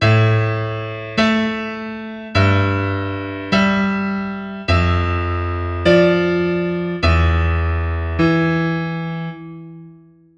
A Largo in 2/4. This piece is a little sleepy, but still flowing.
The presto in 3/8 is a little agitated, and the bass we have added a rhythmic counter motive.